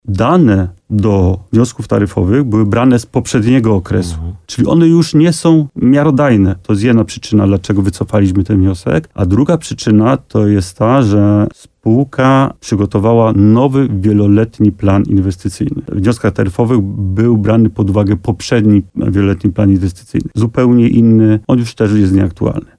w programie Słowo za Słowo w radiu RDN Nowy Sącz